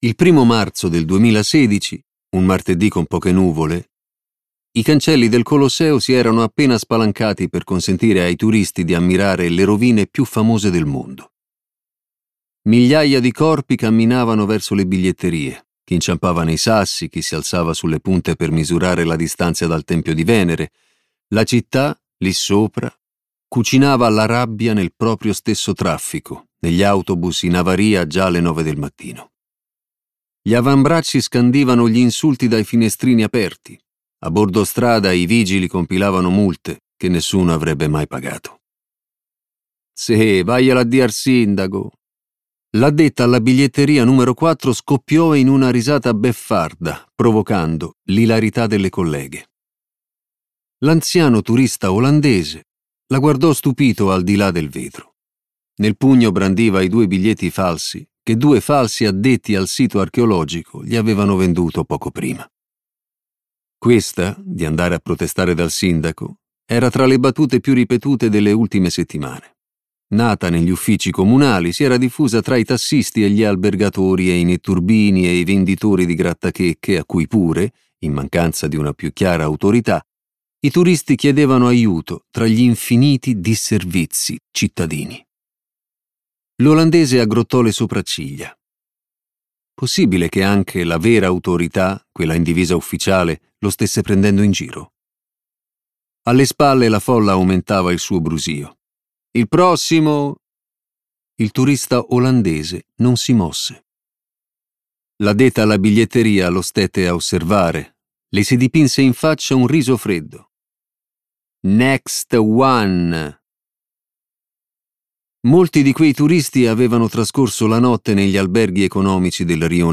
letto da Vinicio Marchioni
Versione audiolibro integrale